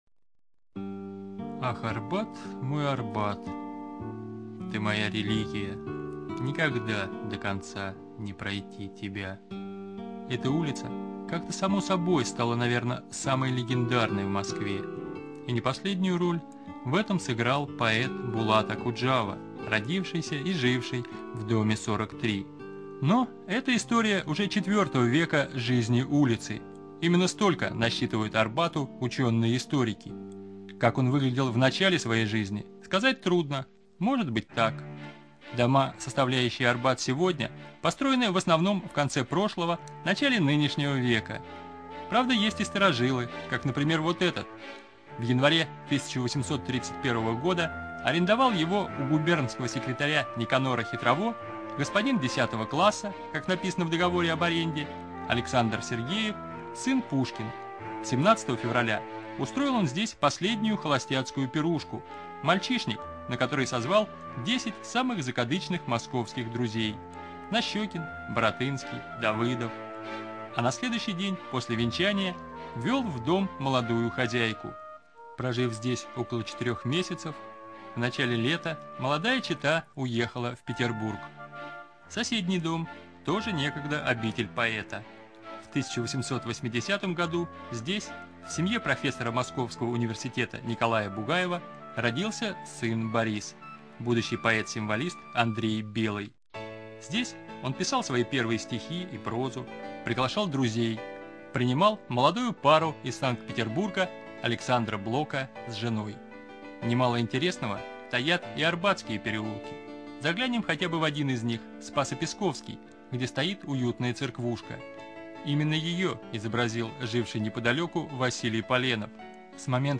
ЖанрАудиоэкскурсии и краеведение